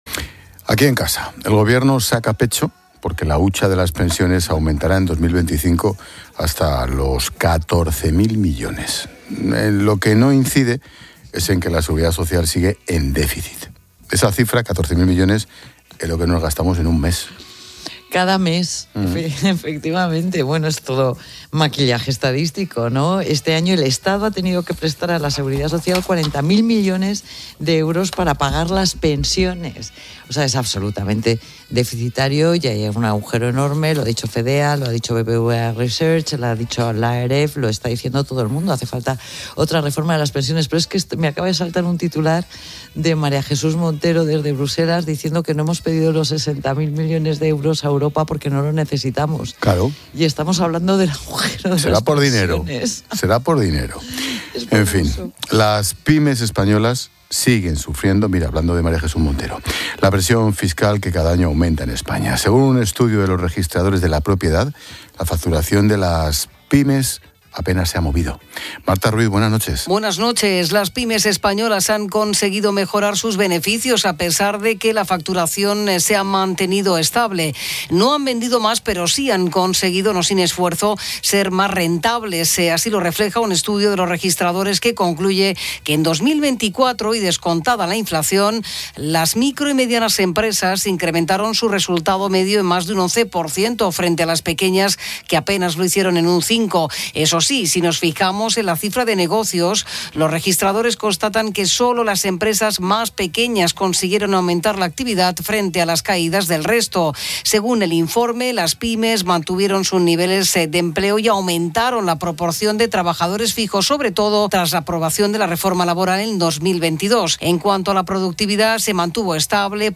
Ángel Expósito y la experta económica y directora de Mediodía COPE, Pilar García de la Granja, analizan la situación de las pymes en España